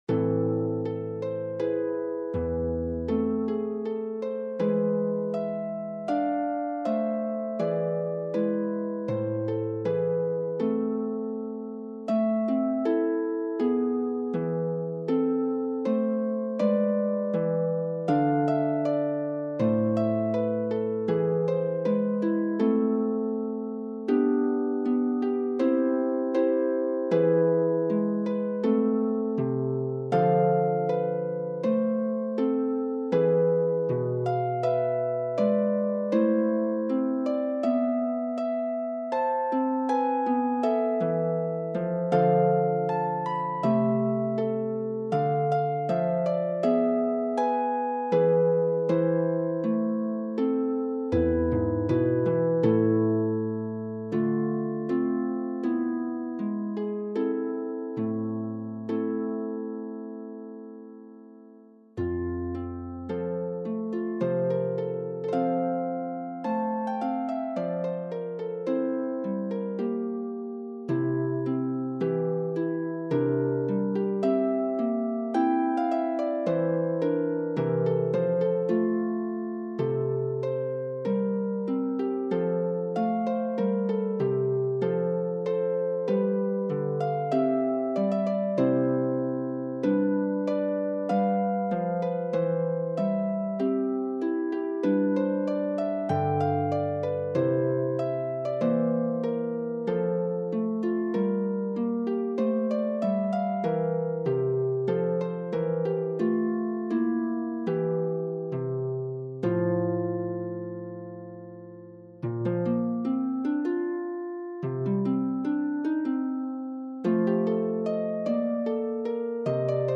for solo lever or pedal harp
A minor
F major
B-flat major